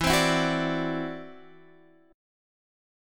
E7sus2#5 chord